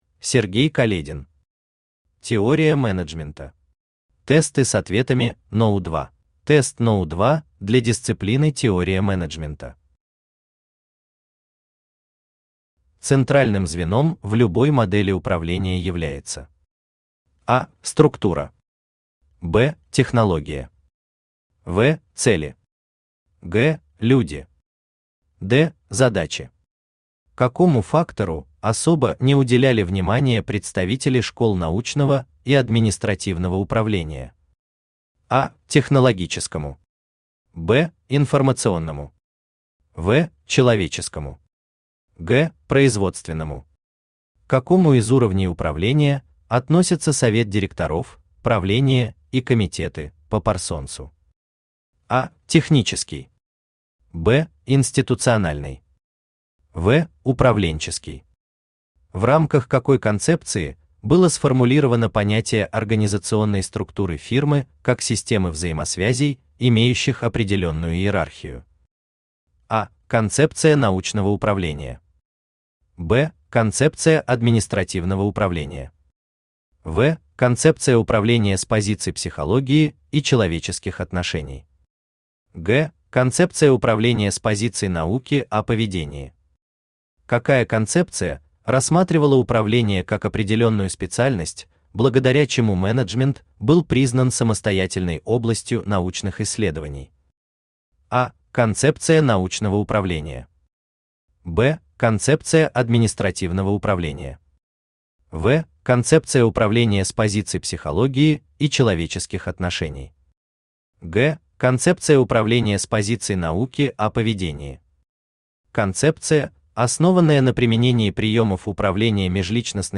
Аудиокнига Теория менеджмента. Тесты с ответами № 2 | Библиотека аудиокниг
Тесты с ответами № 2 Автор Сергей Каледин Читает аудиокнигу Авточтец ЛитРес.